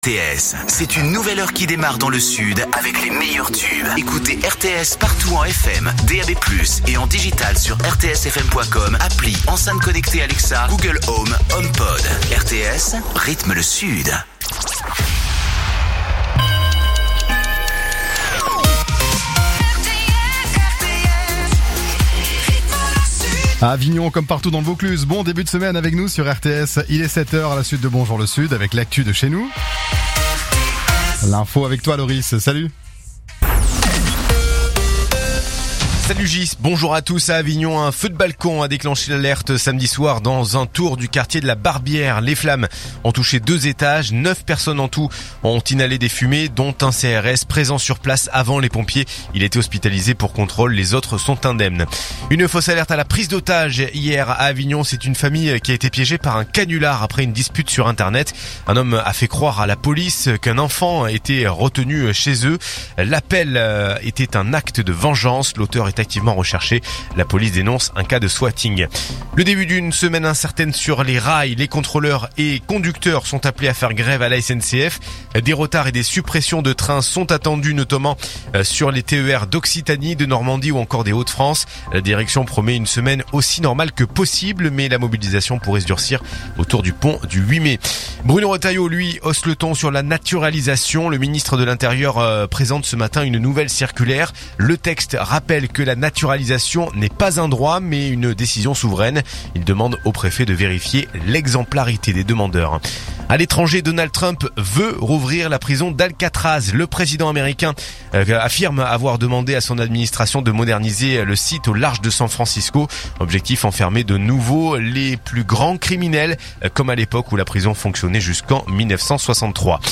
Écoutez les dernières actus d'Avignon en 3 min : faits divers, économie, politique, sport, météo. 7h,7h30,8h,8h30,9h,17h,18h,19h.